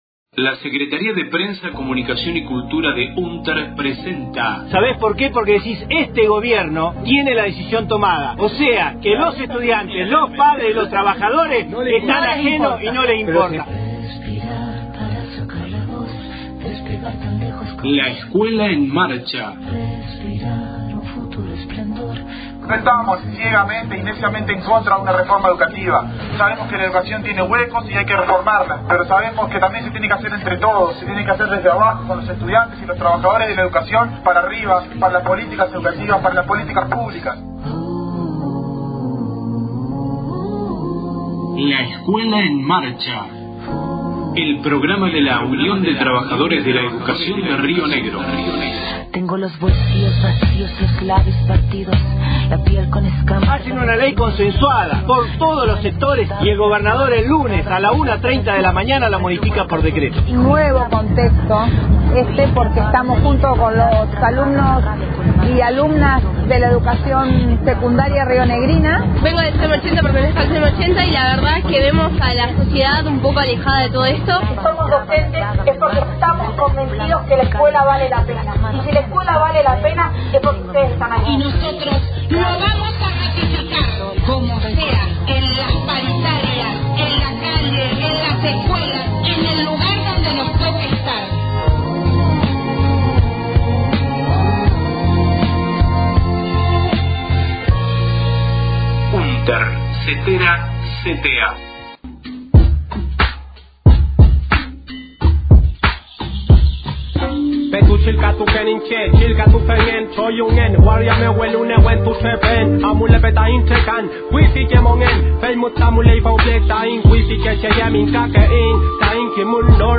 audio conferencia de prensa